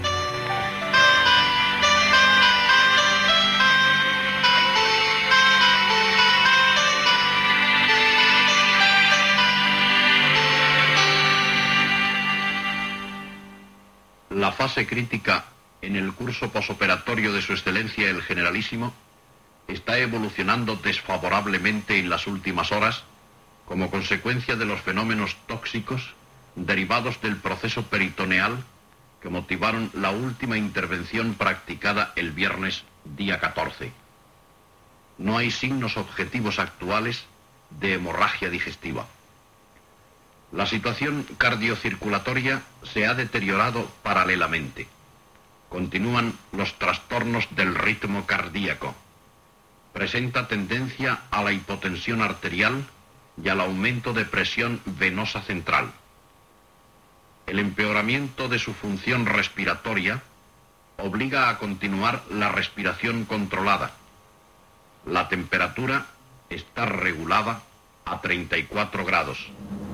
Lectura del comunicat mèdic del "Generalísimo" Francisco Franco després de la intervenció quirúrgica del 14 de novembre.
Informatiu